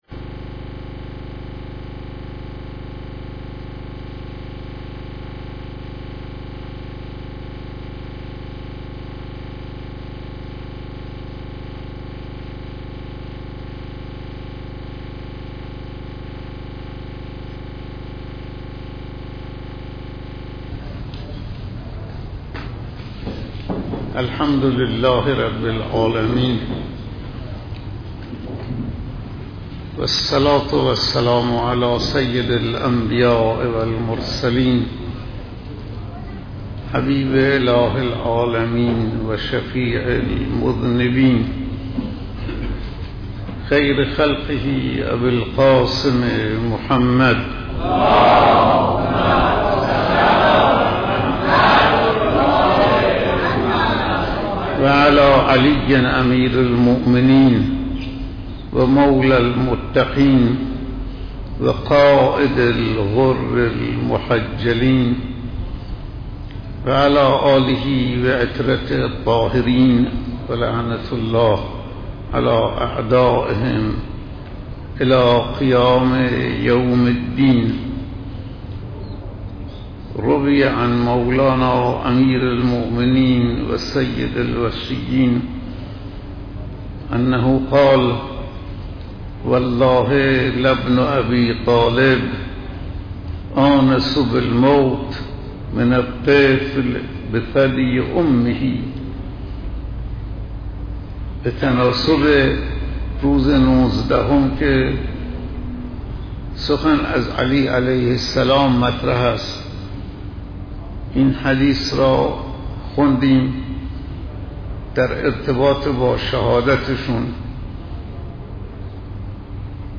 سخنرانی روز ۱۹ رمضان - صوتی